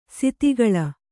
♪ siti gaḷa